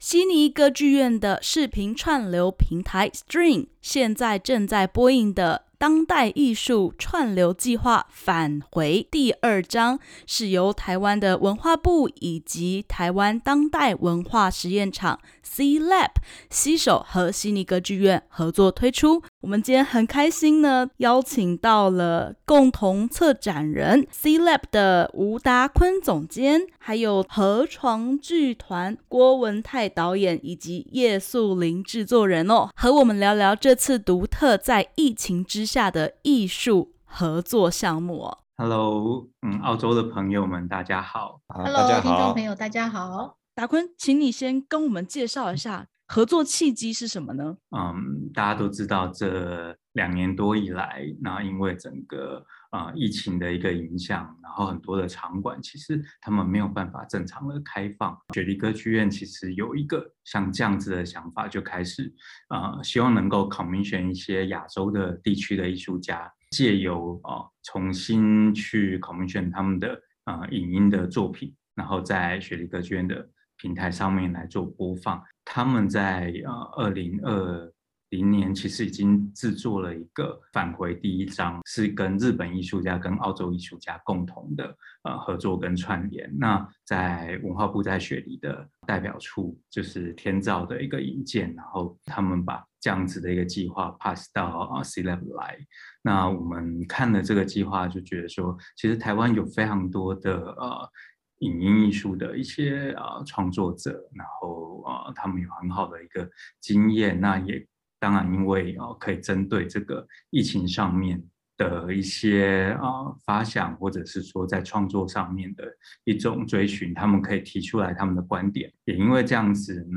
悉尼歌剧院与台湾艺文团队所打造的“返回：第二章”影像作品展，现正于歌剧院在线平台Stream播映中。 （点击首图收听采访音频）